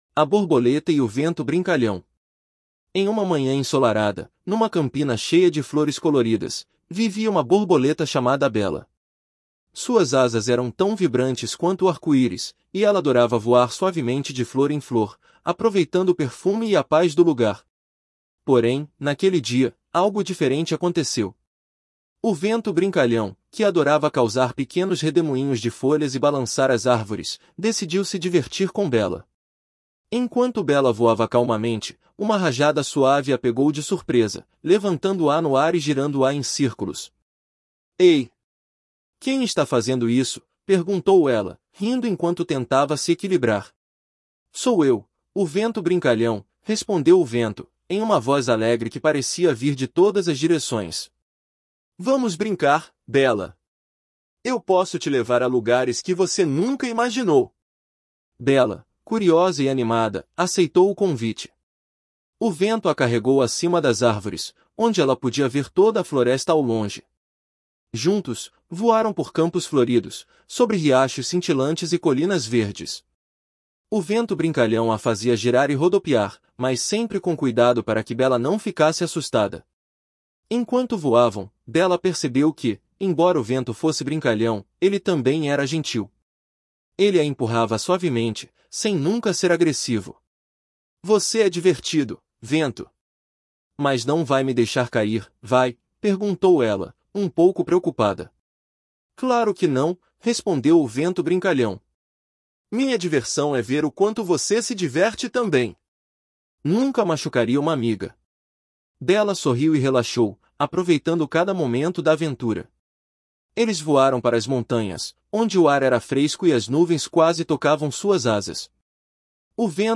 História infantil de borboleta